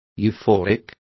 Also find out how euforico is pronounced correctly.